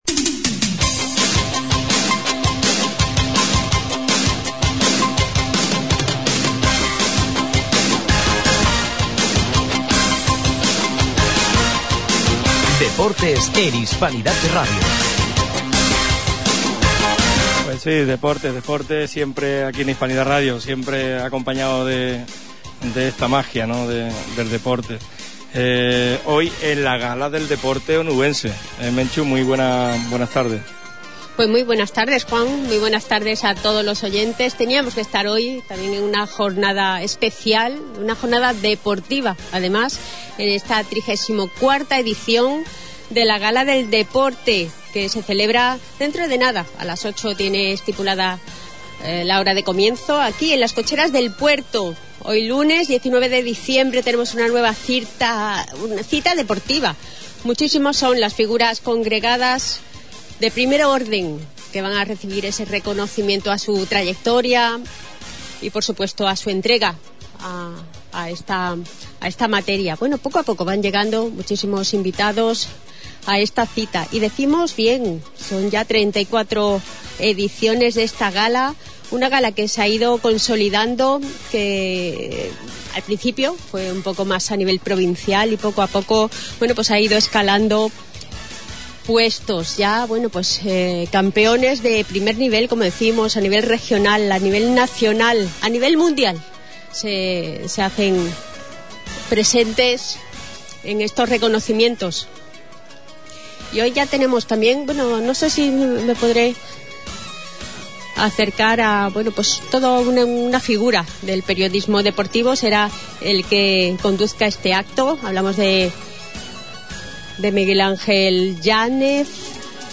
A las 20 horas, en las Cocheras del Puerto, ha tenido lugar la XXXIVº Gala del Deporte de Huelva, organizada por la Asociación Onubense de la Prensa Deportiva (AOPD).